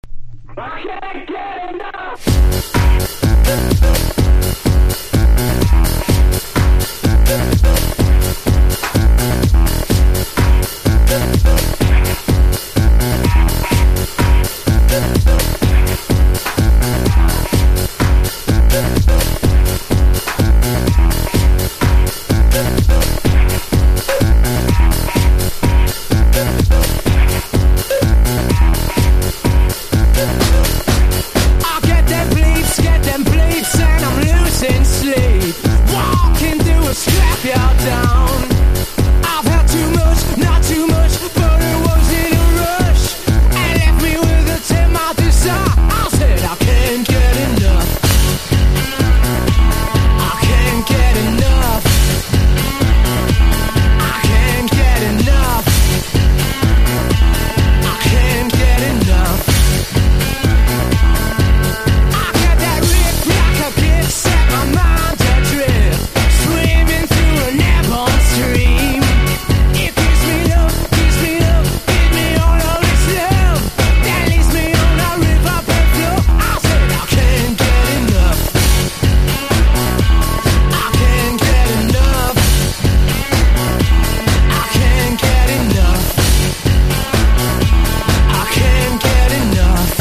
パンキッシュなディスコ・ビートにファンキーなギターをプラスしたロッキン･ディスコ！